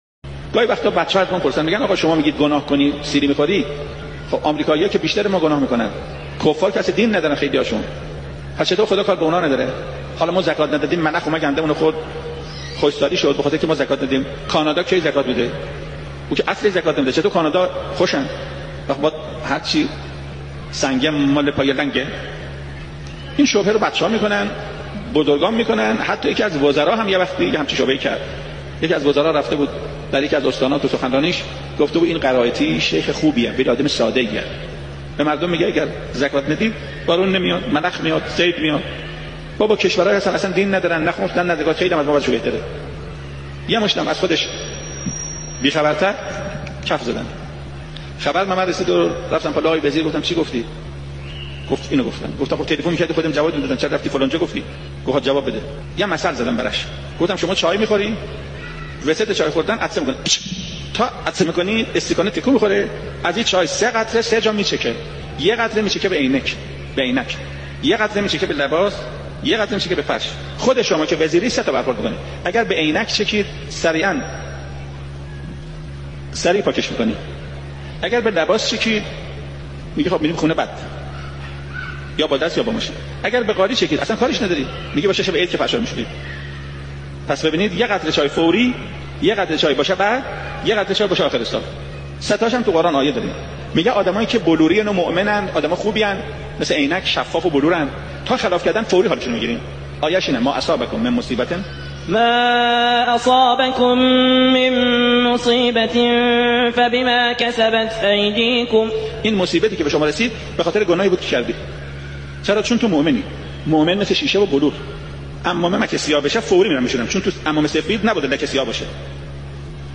عقیق: استاد قرائتی در یکی از سخنرانی های خود به شبهه‌ای پیرامون «چرایی بارش باران در بلاد کفار و کمبود آن در کشورهای مسلمان» پرداخت که تقدیم شما فرهیختگان می شود.